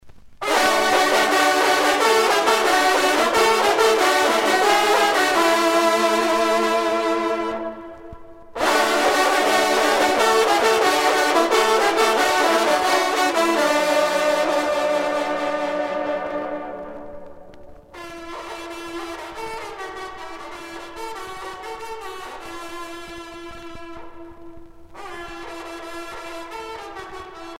trompe - fanfare
circonstance : vénerie
Pièce musicale éditée